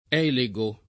elego [ $ le g o ]